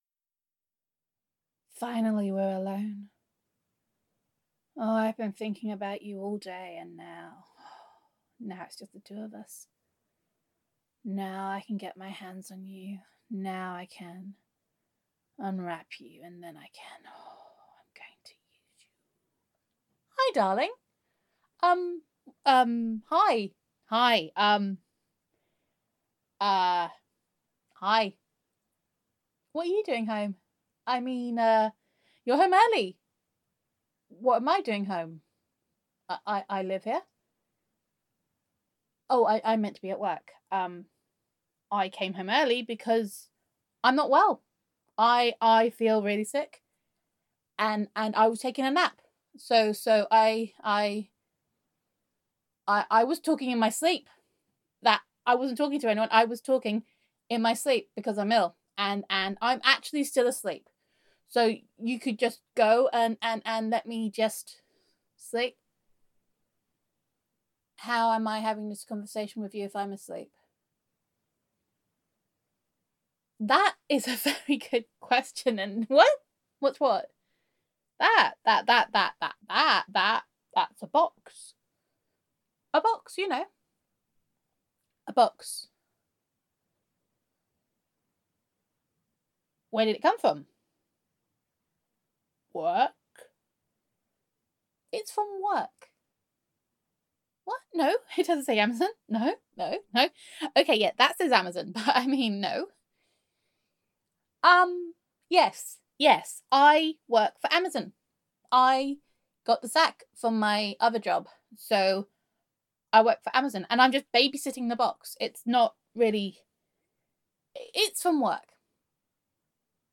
[F4A] I Don’t Have a Problem [Domestic][Adorkable][Skincare Addiction][Adorably Underhand][Sneakiness][Tall Tales][Honey Is Super Duper Dodge][Gender Neutral][Your Girlfriend Is Acting Very Suspiciously About a Mystery Parcel]